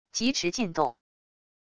疾驰进洞wav音频